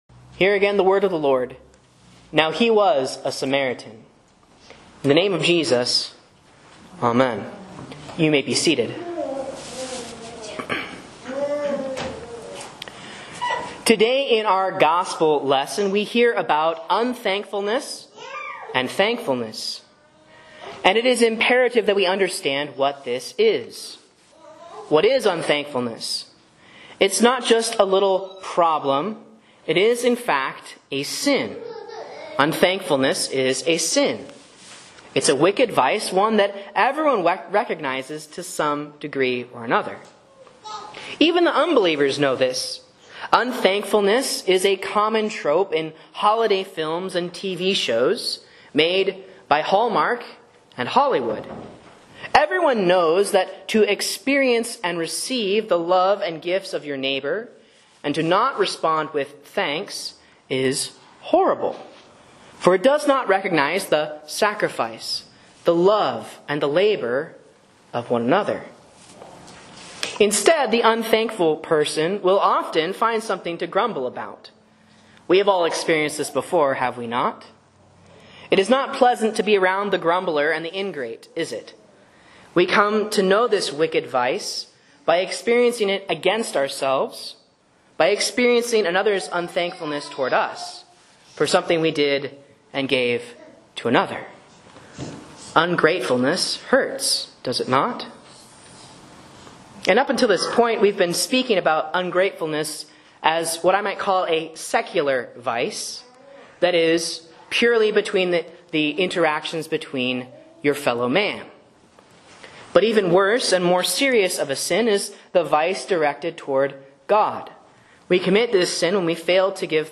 Sermon and Bible Class Audio from Faith Lutheran Church, Rogue River, OR